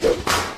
whapoosh.ogg